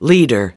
29 leader (n) /ˈliːdər/ Người lãnh đạo